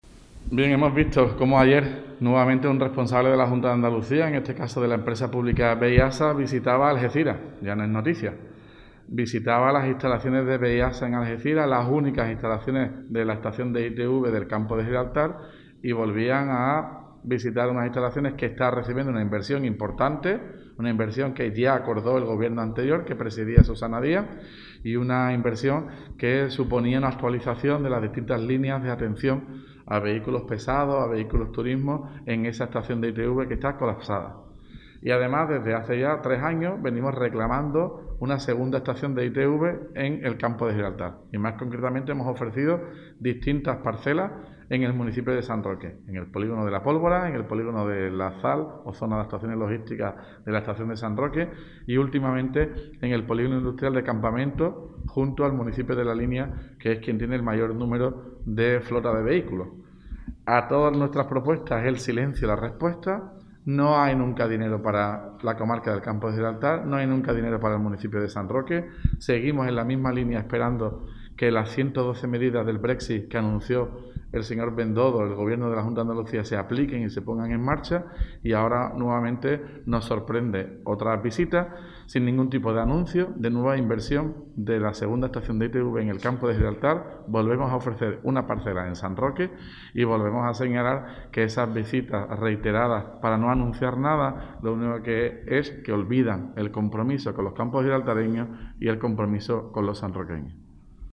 RESPUESTA ALCALDE VISITA JUNTA ANDALUCIA ALGECIRAS.mp3